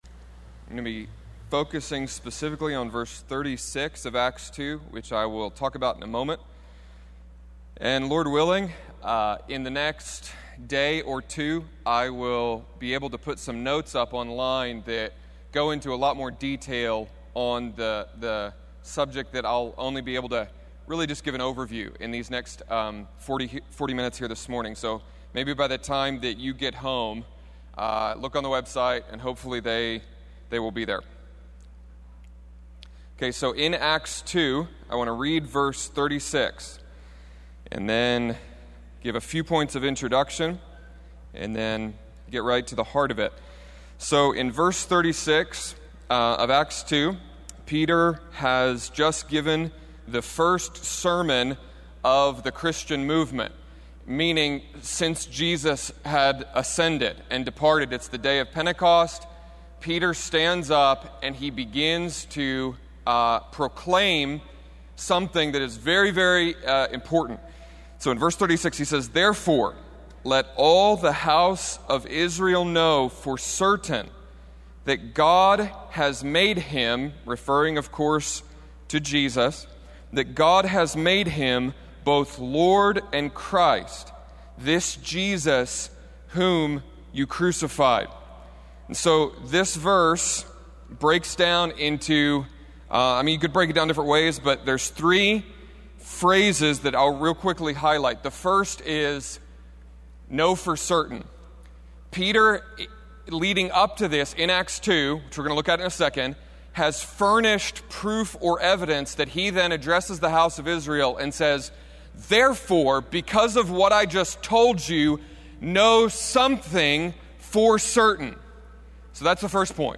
Speaker
Service Onething 2011